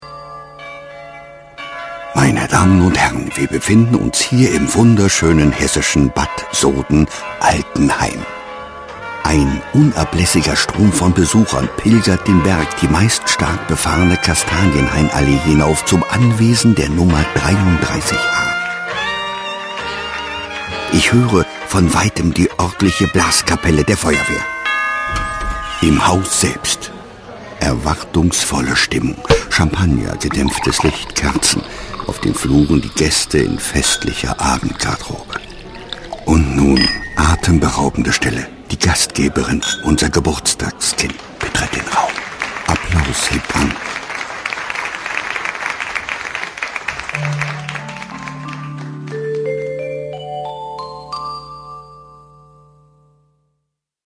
deutscher Sprecher
berlinerisch
Sprechprobe: Werbung (Muttersprache):
german voice over talent